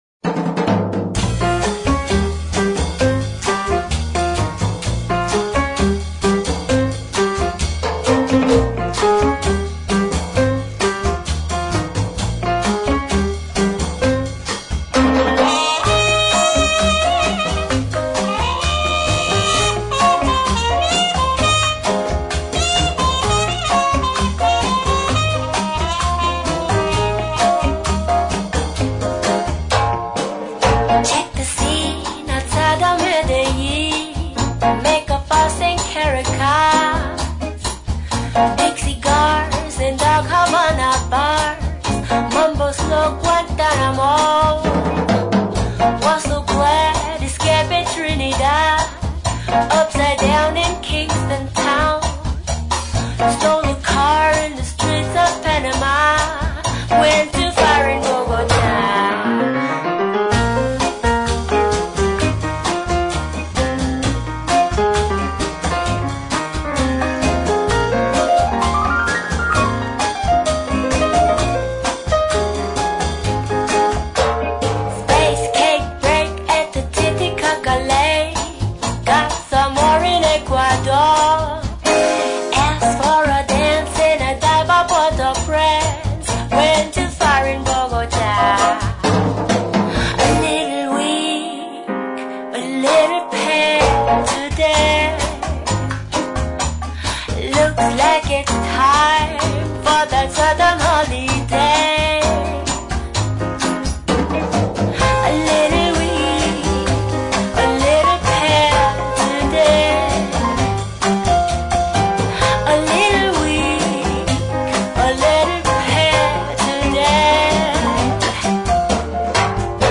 Blues Jazz Para Ouvir: Clik na Musica.